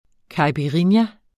Udtale [ kɑjpiˈʁinja ]